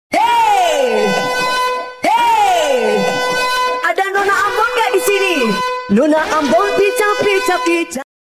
Genre: Nada dering alarm